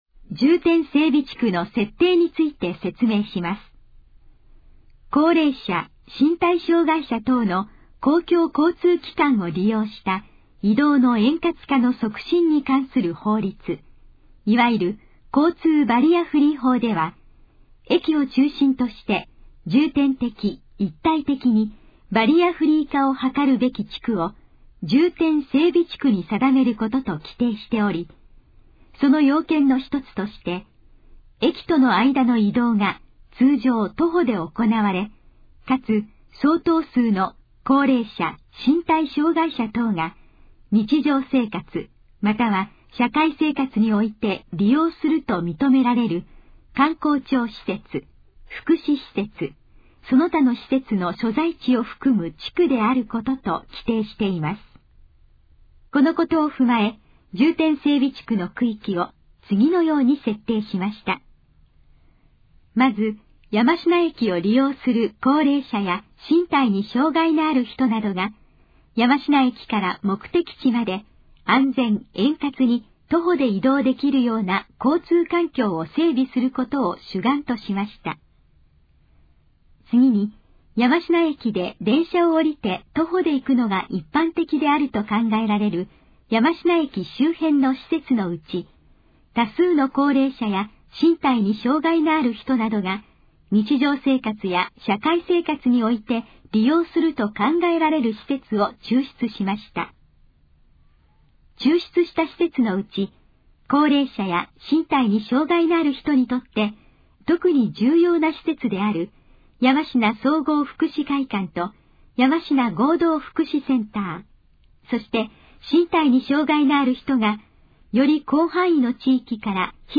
このページの要約を音声で読み上げます。
ナレーション再生 約328KB